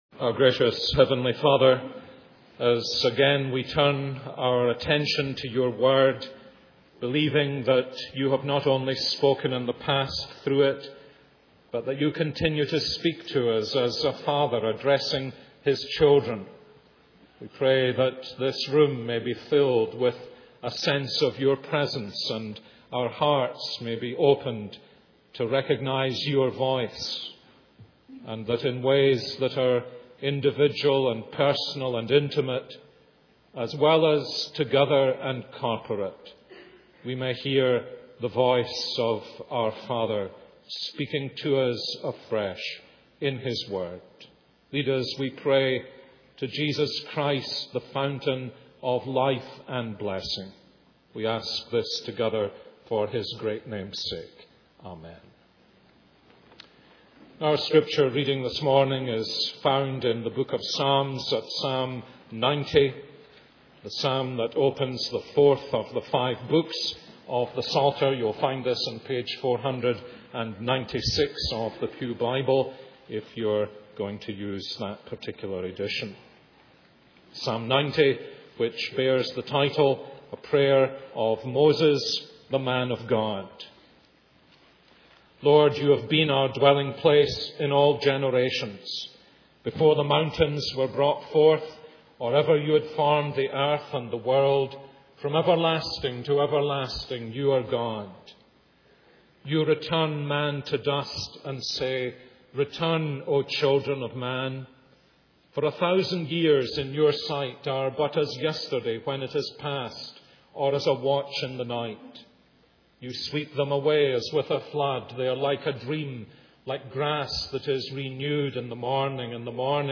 This is a sermon on Psalm 90:1-17.